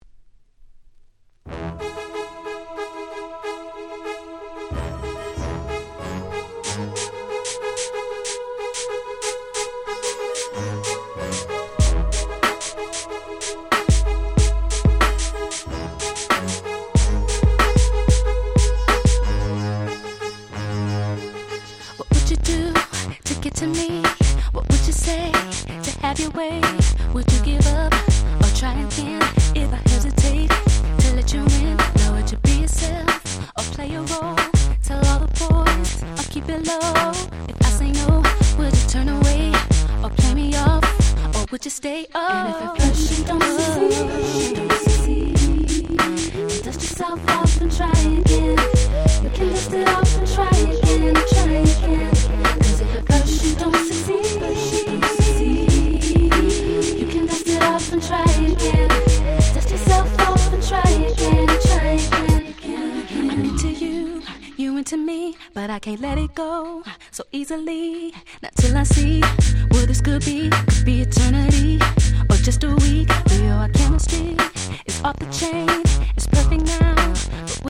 00' Nice R&B / Hip Hop Soul !!